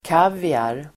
Ladda ner uttalet
Uttal: [k'av:iar]
kaviar.mp3